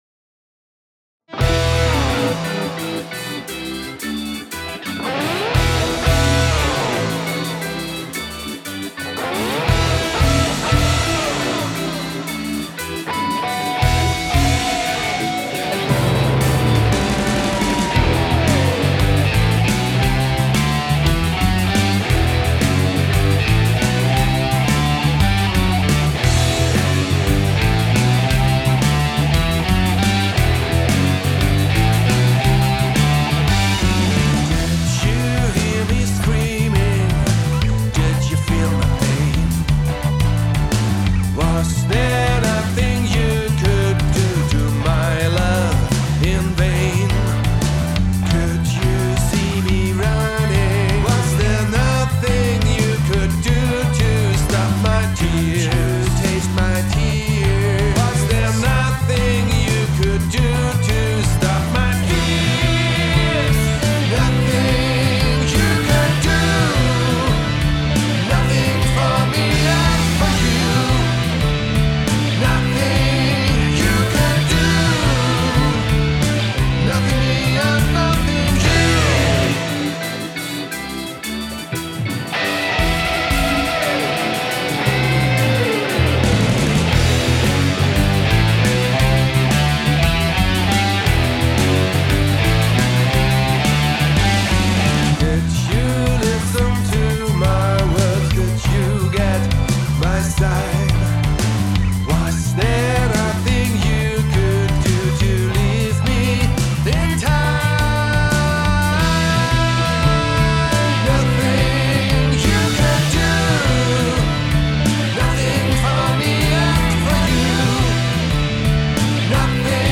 Nothing - Rock aus einer anderen Zeit Teil 2